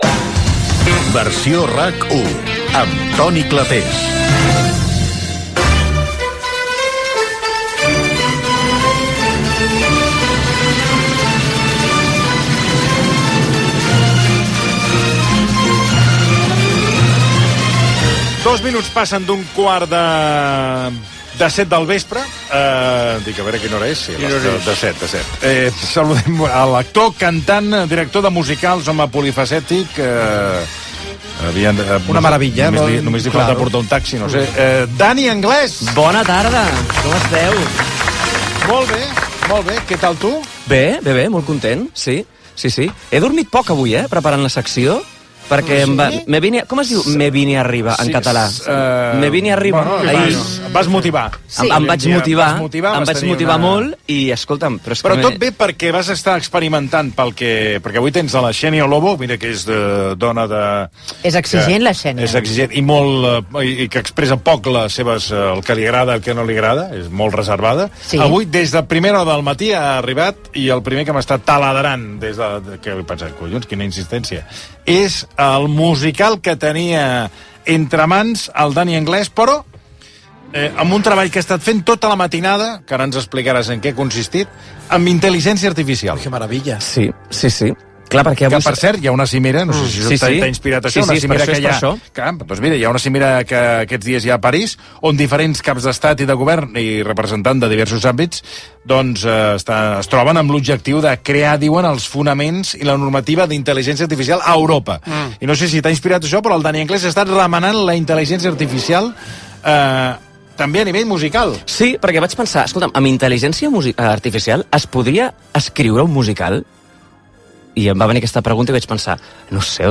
Indicatiu del programa, secció dedicada als musicals amb l'estrena de peces dedicades al programa i el seu equip, fetes amb programaris d'intel·ligència artificial, com Chat GPT, per celebrar les 6000 edicions del programa, Indicatiu, publicitat, indicatiu i espai publicitari Gènere radiofònic Entreteniment